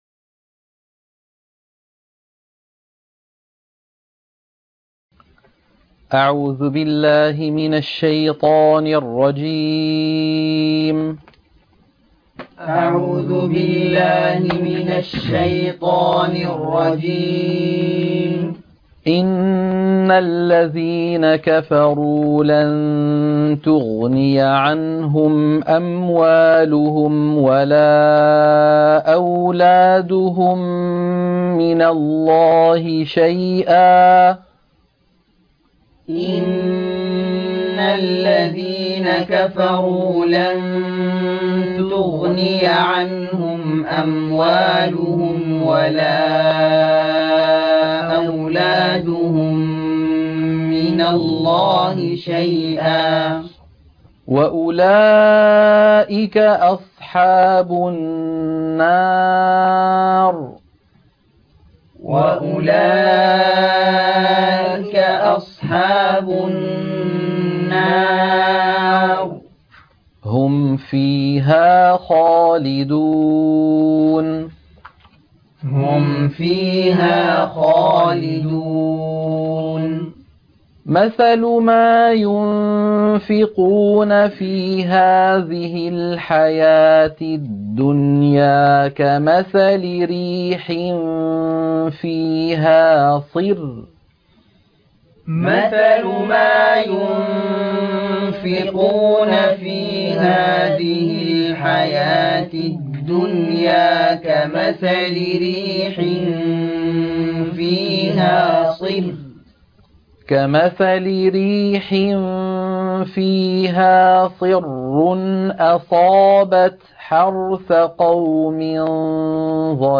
عنوان المادة تلقين سورة آل عمران - الصفحة 65 التلاوة المنهجية